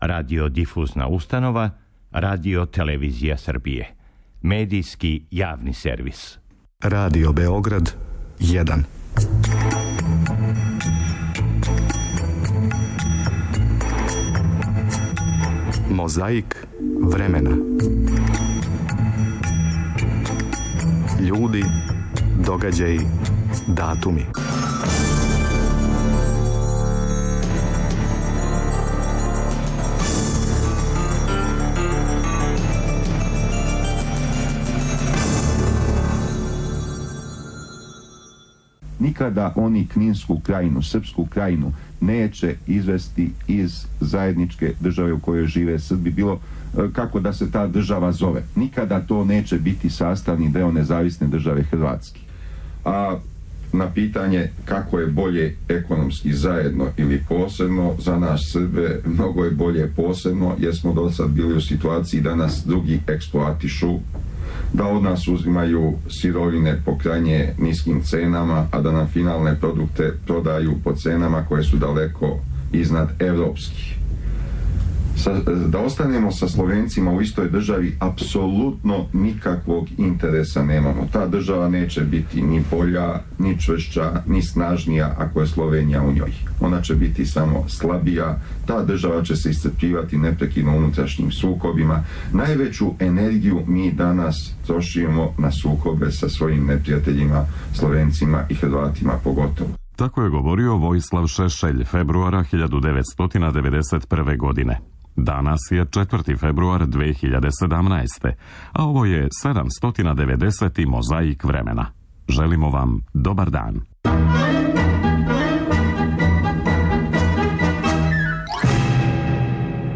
Свечано отварање са стадиона „Кошево" преносиле су и Удружене радио станице Социјалистичке Федеративне Републике Југославије.